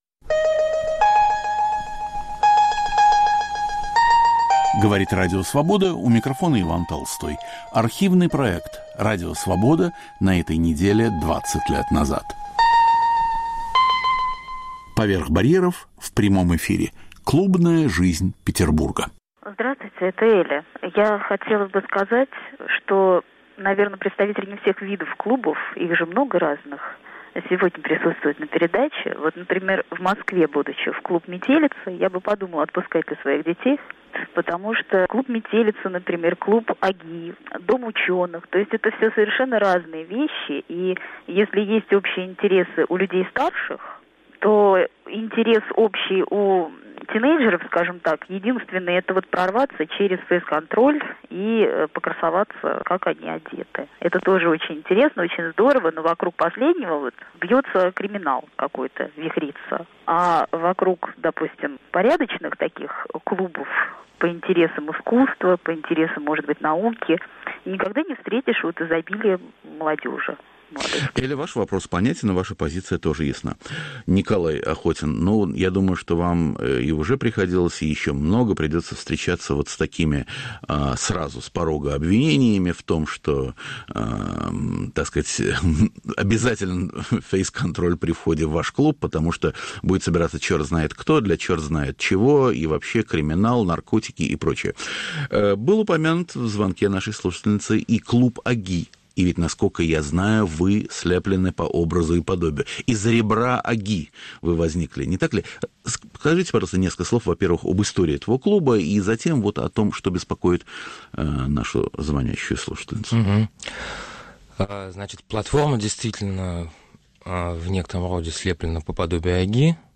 "Поверх барьеров" в прямом эфире. Клубная жизнь Петербурга